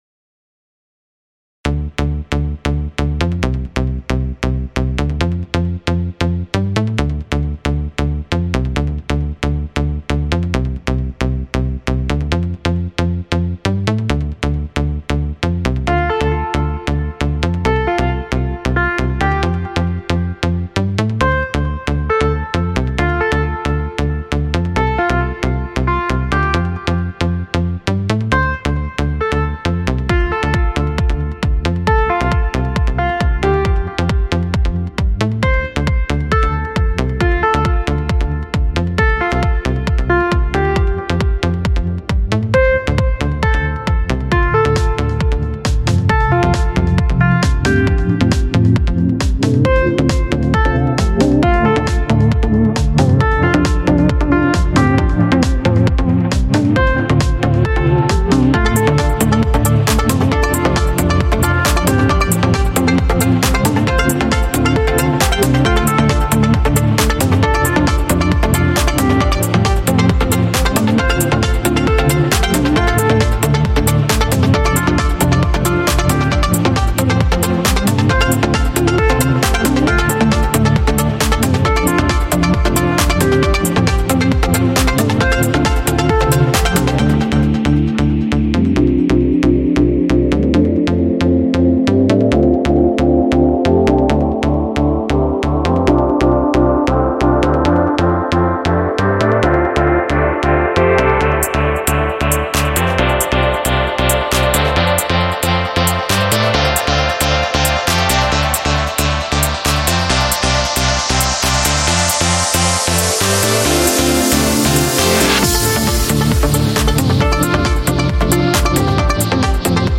genre:trance